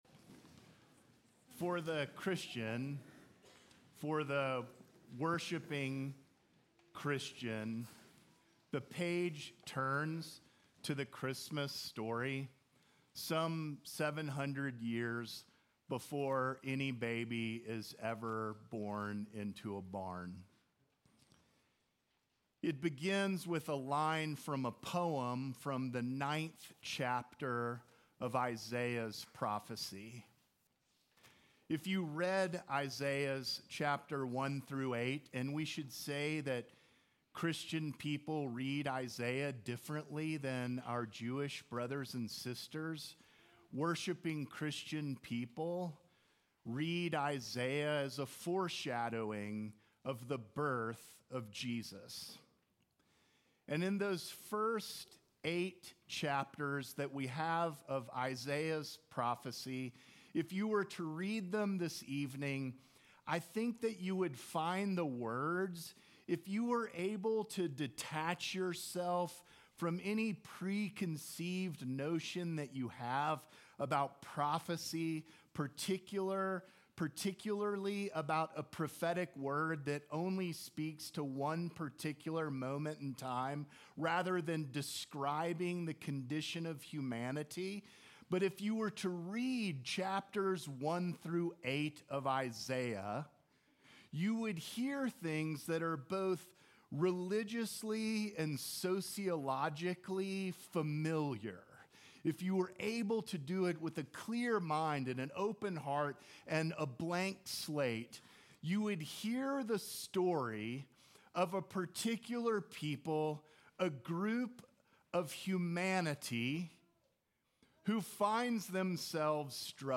Sermons
St. John's Episcopal Church